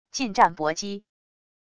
近战搏击wav音频